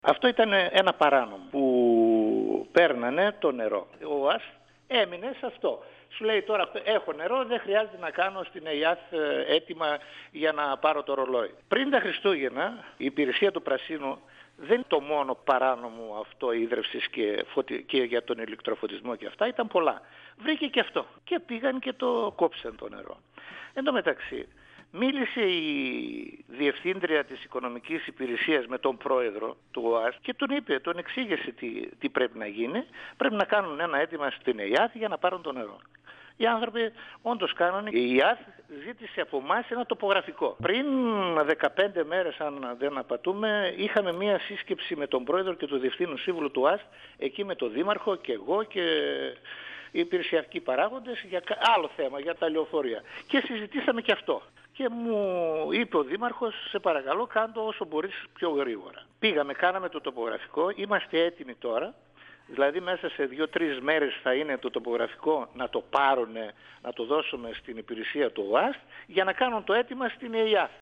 Ο αντιδήμαρχος Τεχνικών Εργων στο δήμο Παύλου Μελά, Στέφανος Βάρφης, στον 102FM του Ρ.Σ.Μ. της ΕΡΤ3
Συνέντευξη